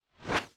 WHOOSH_Long_stereo.wav